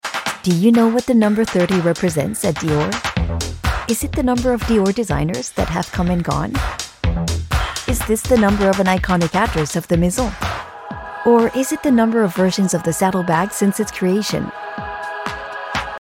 VOIX GRAVE ET ÉLÉGANTE POUR LVMH (anglais)